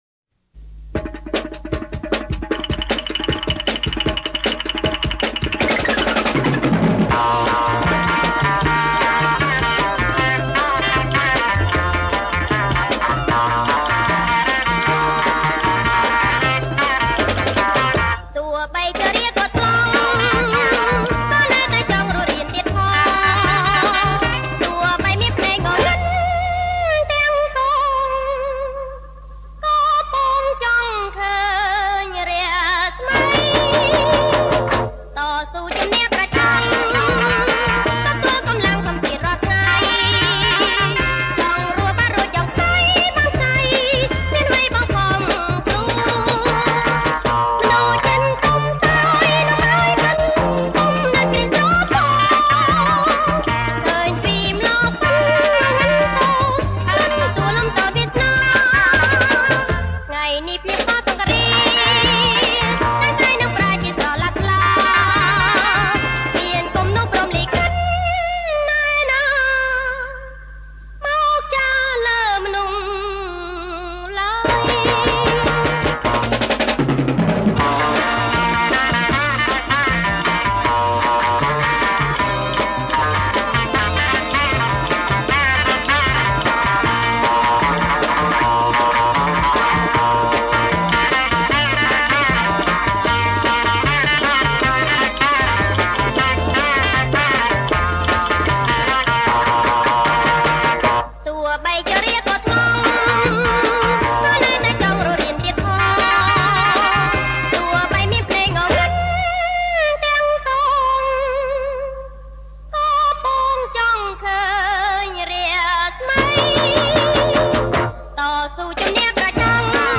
• ប្រគំជាចង្វាក់ Jerk